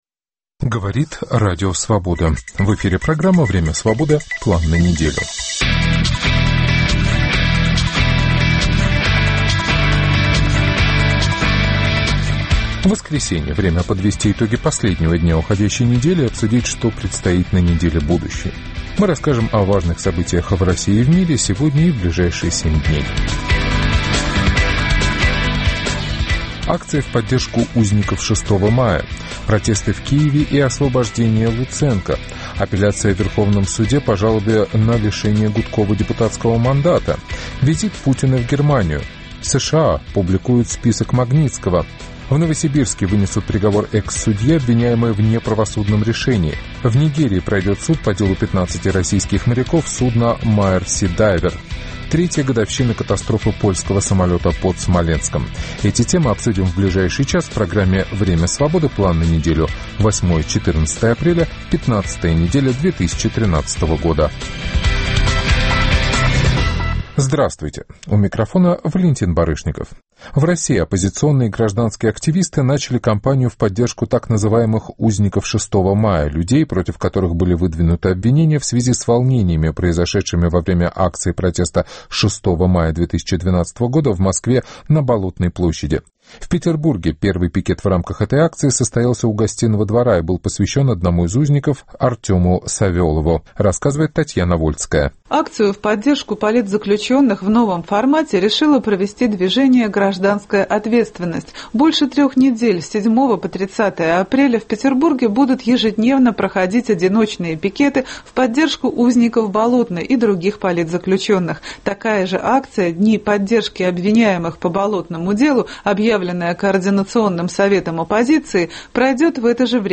Программу попеременно ведут редакторы информационных программ в Москве и Праге.